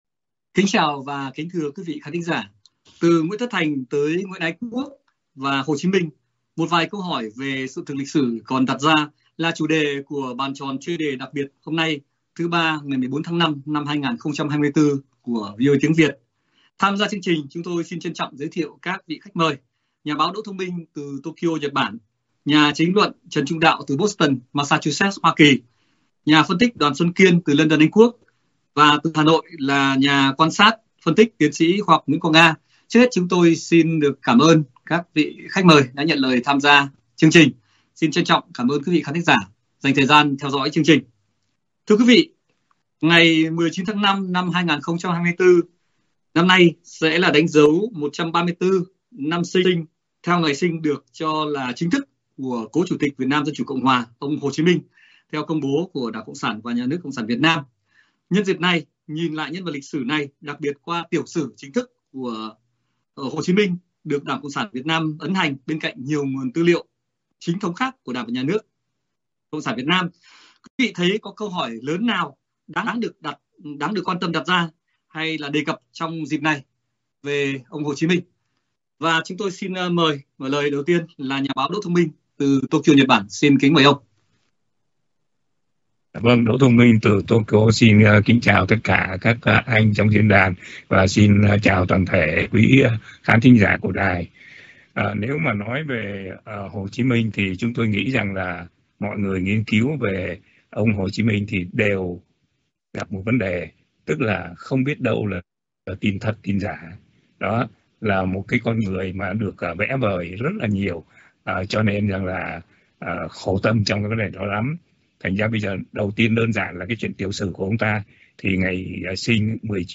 Các nhà quan sát, bình luận chính trị, lịch sử và báo chí từ Việt Nam và hải ngoại thảo luận một vài khía cạnh liên quan nhân 134 năm sinh cố Chủ tịch VNDCCH ông Hồ Chí Minh và một số câu hỏi đặt ra về sự thật lịch sử liên quan nhân vật lịch sử này và Việt Nam cận đại.